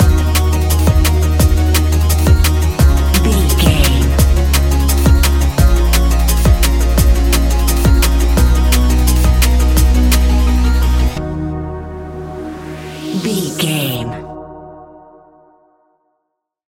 Ionian/Major
D♯
techno
trance
synths
synthwave